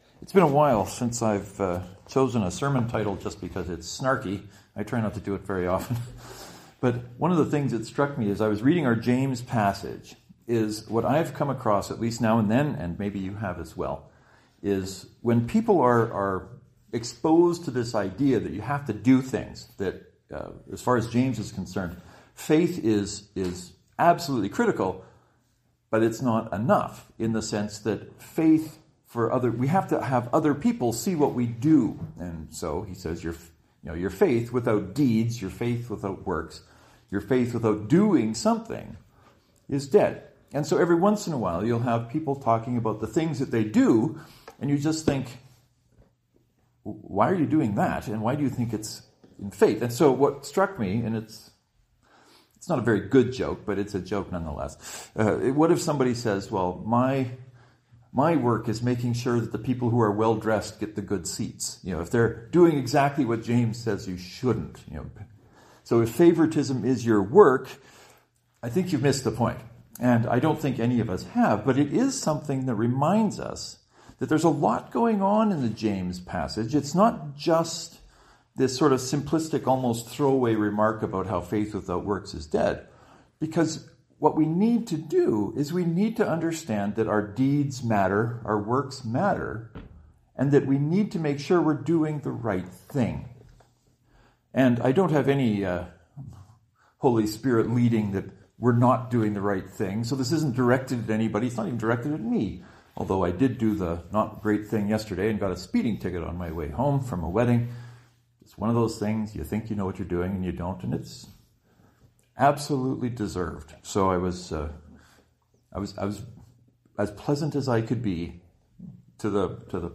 Knox Presbyterian